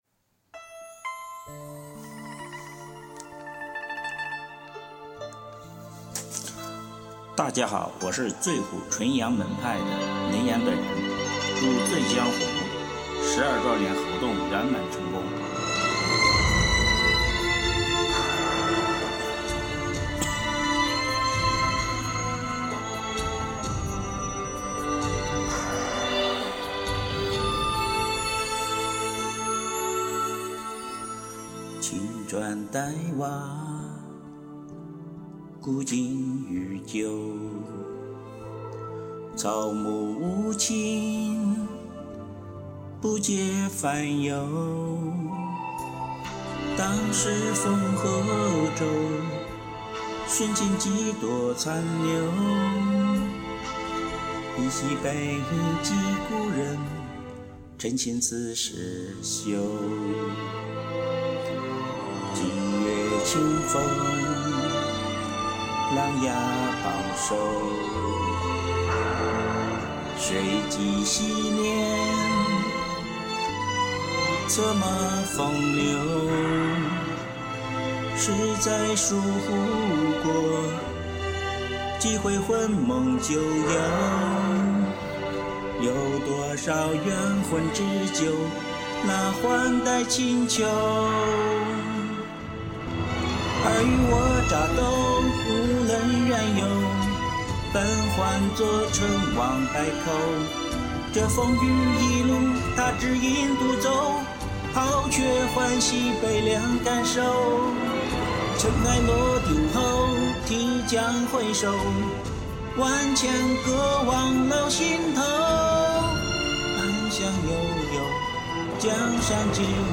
我这嗓子，曾经也是圆润如意高低音男女声切换自如的，如今却只能捏着嗓子勉强唱完一首歌。